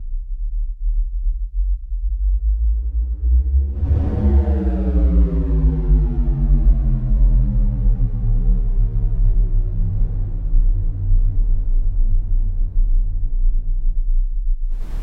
Rizz bass drop long.mp3